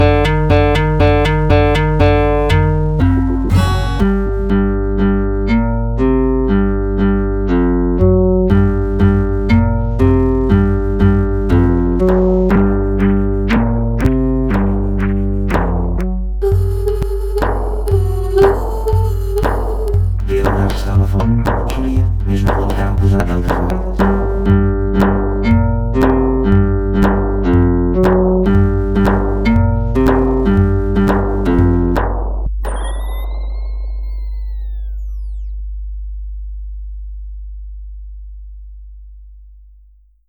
Category 🎵 Music